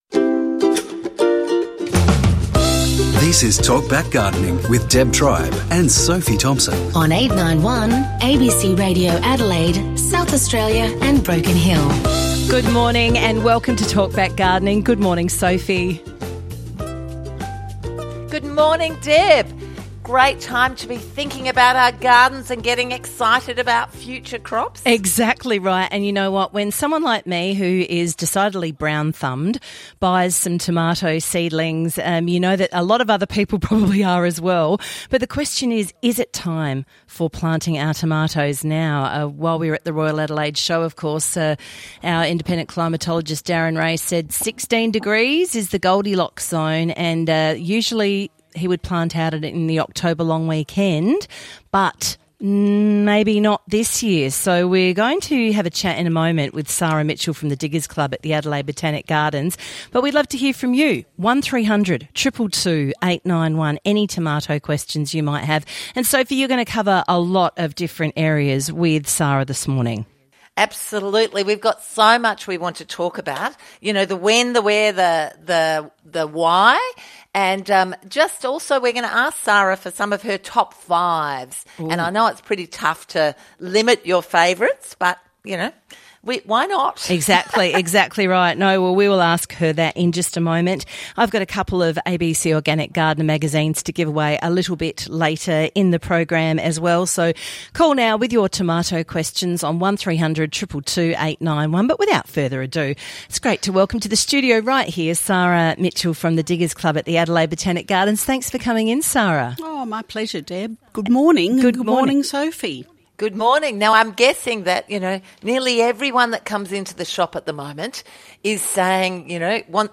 Talkback Gardening